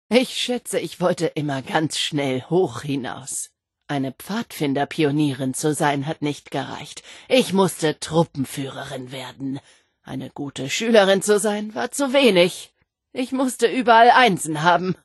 Datei:Vault-76-Aufseherin 0003ea9d 3.ogg
Wastelanders: Audiodialoge